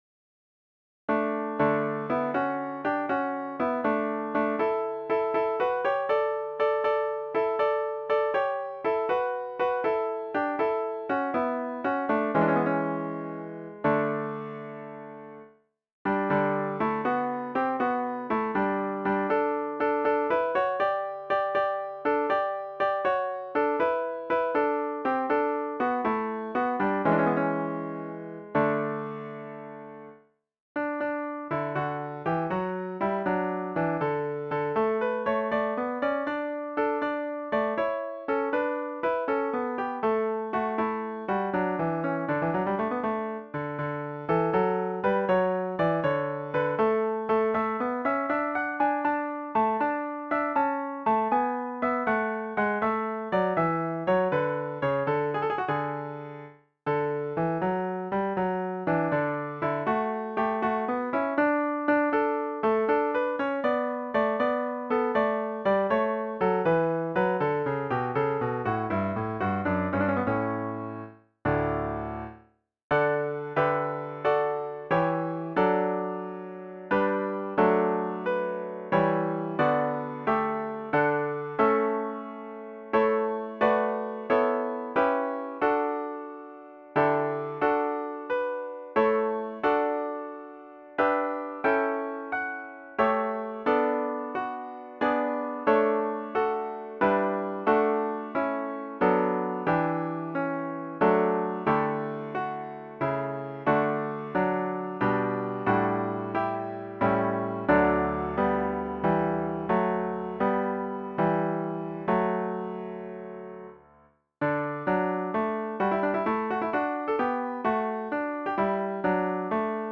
for piano (organ)  (2, 3, 4 parts)